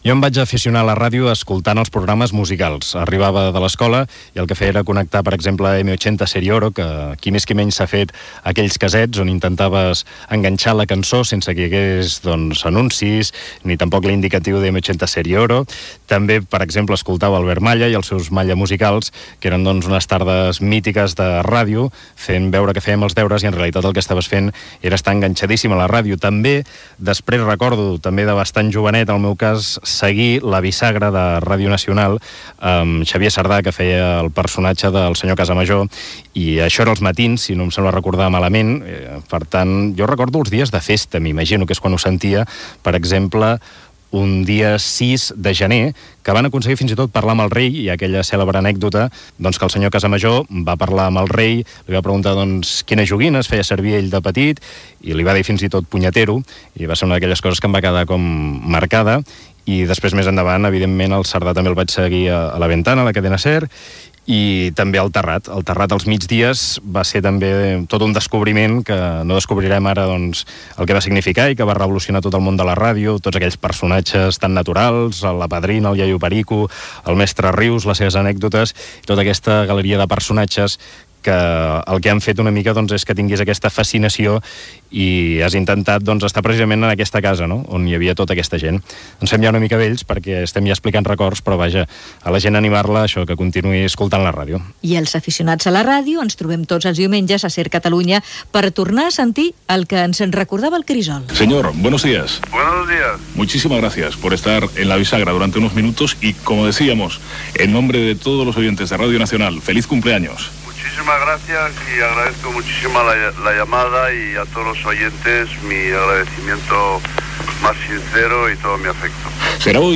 S'inclou un fragment de "La bisagra" en el qual es va entrevistar al Rei Juan Carlos I.